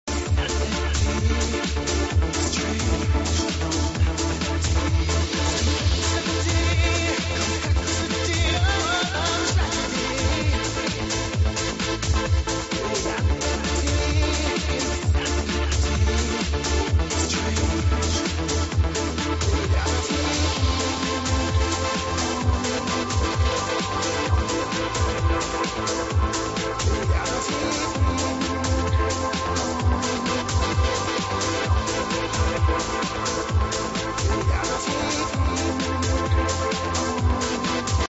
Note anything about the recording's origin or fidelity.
I can't that is problem...I record end of song from TV, and cut only what I recorded....Before few mounts I saw same song on TV too and there was no name...So like this song, and that is end of song Thanks for try anyway...